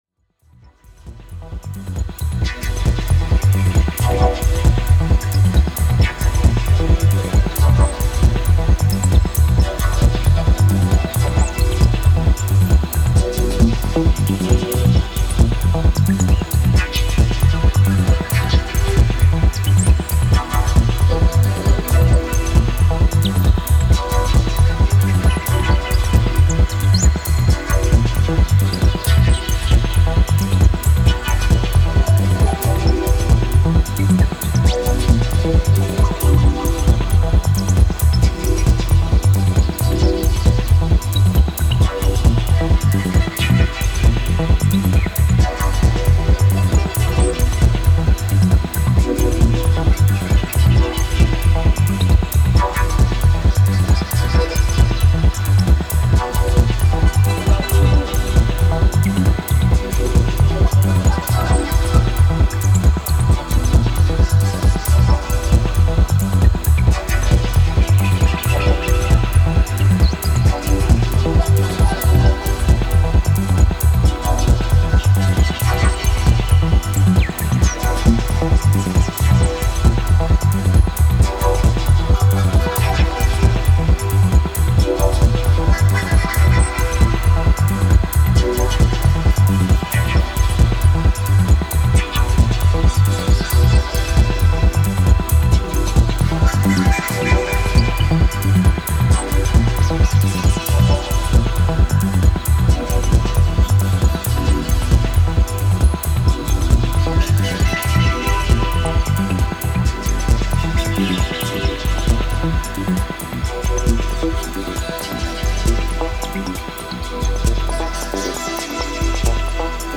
Styl: House, Techno, Minimal